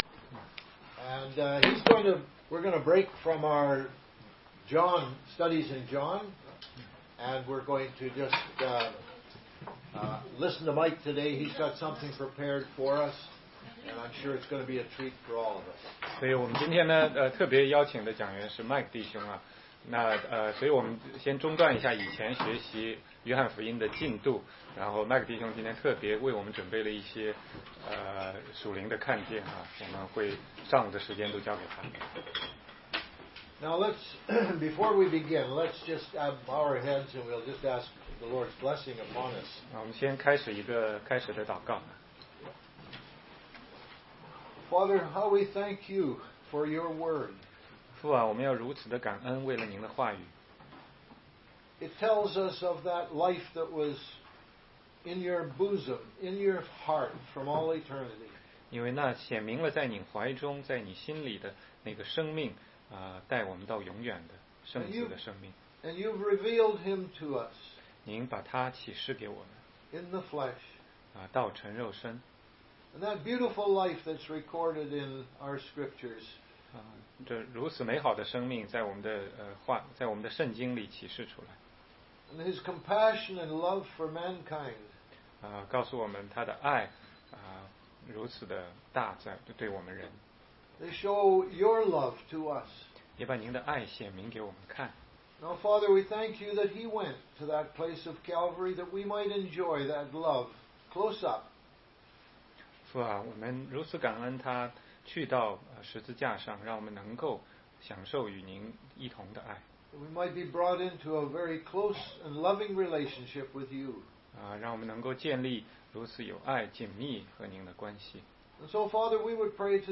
16街讲道录音 - 详解该隐和亚伯